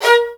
STR ATTACK0D.wav